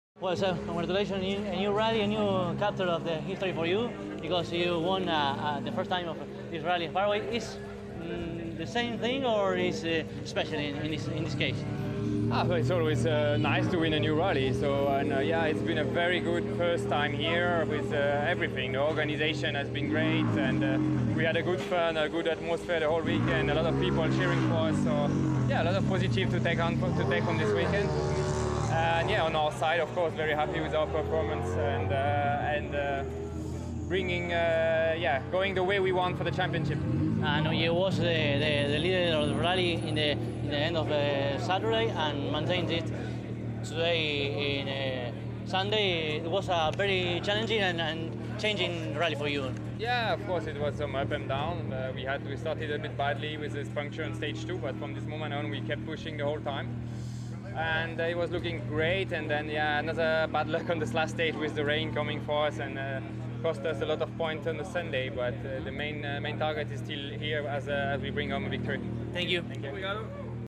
El domingo, luego de la carrera, dialogamos con los tres primeros del clasificador general, que los podrás escuchar en esta nota.
Así entonces, a continuación, podrás escuchar las entrevistas que realizamos (en inglés) con cada uno de ellos: